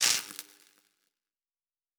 pgs/Assets/Audio/Sci-Fi Sounds/Electric/Spark 19.wav at master
Spark 19.wav